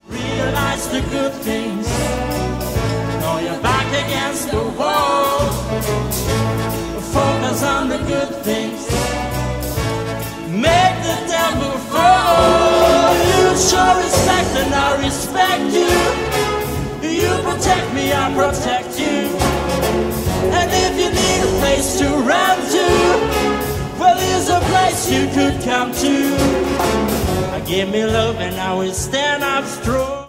Gattung: Solo für Gesang und Symphonisches Blasorchester
Besetzung: Blasorchester